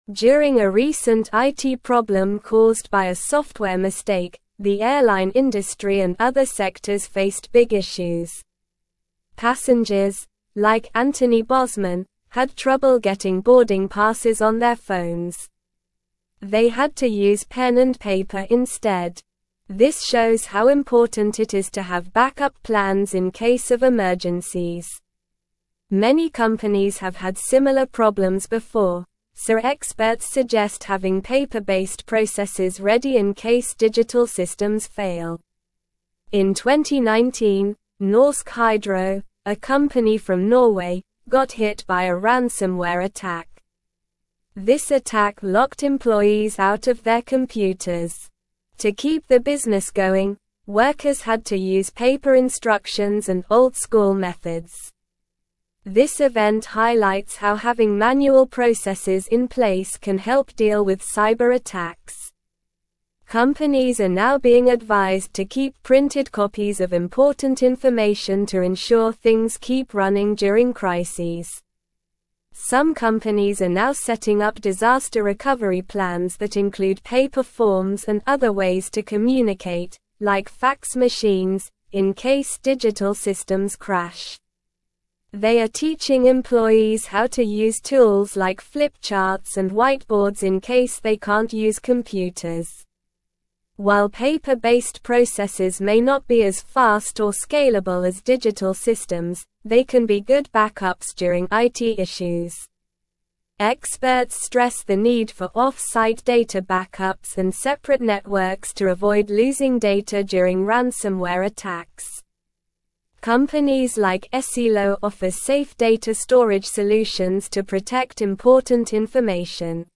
Slow
English-Newsroom-Upper-Intermediate-SLOW-Reading-The-Importance-of-Manual-Processes-During-IT-Failures.mp3